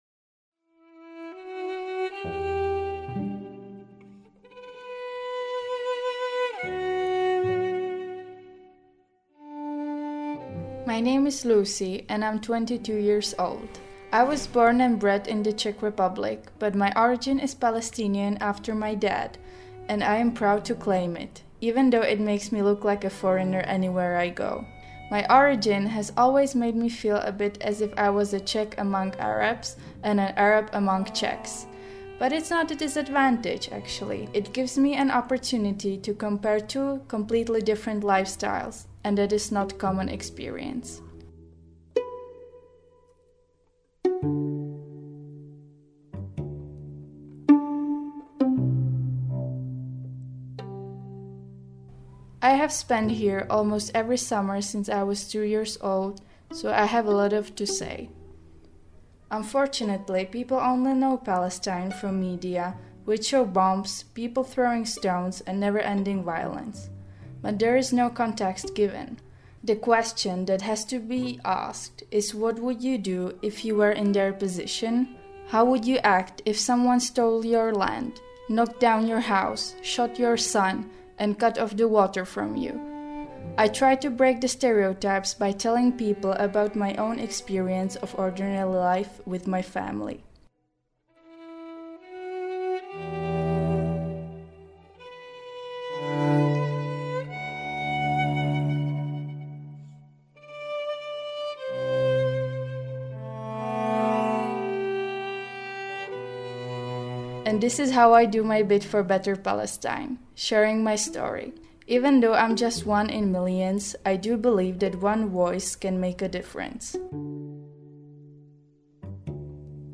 Music by : Le Trio Joubran